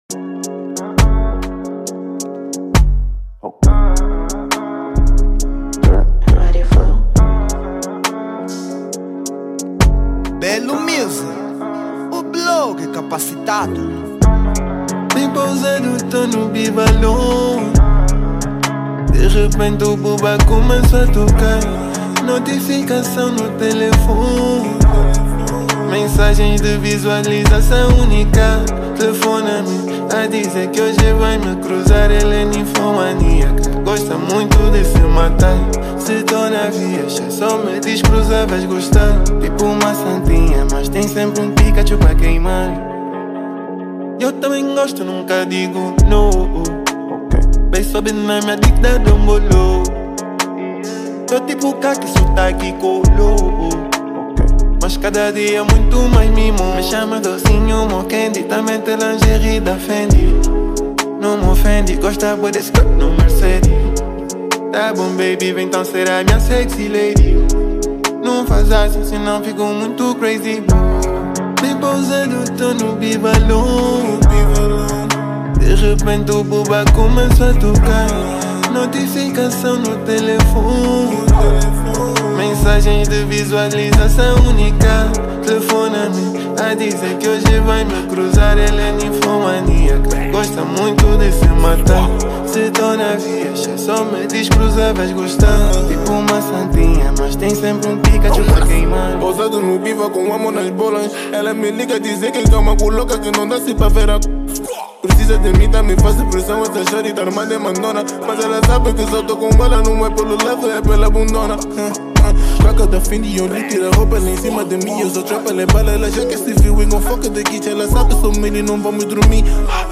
Género : Trap